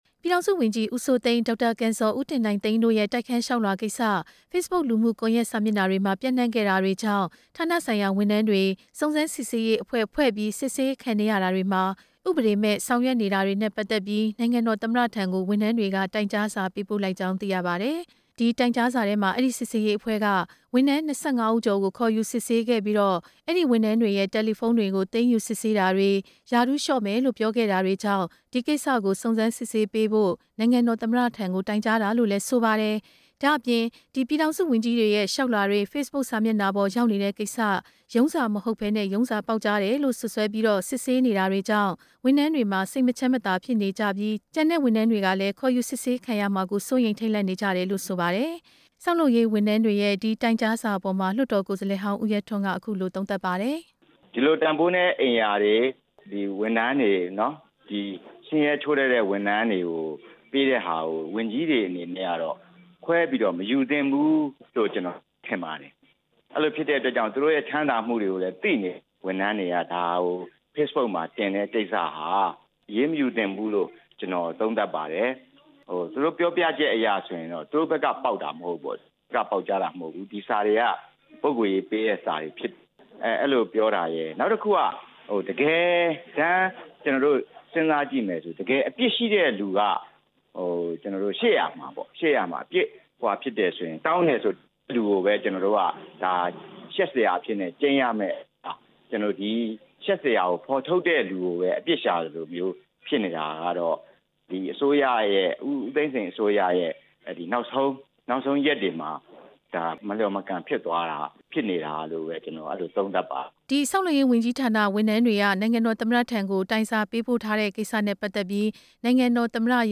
သမ္မတရုံးဝန်ကြီးတွေ တိုက်ခန်းတောင်းတဲ့ကိစ္စ တင်ပြချက်